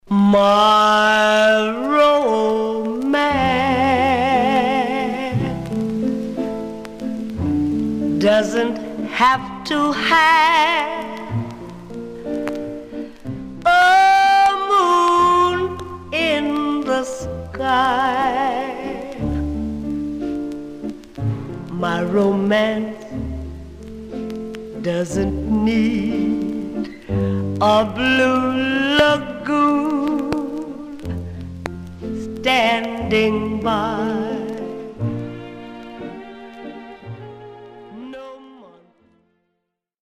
Stereo/mono Mono
Rythm and Blues Condition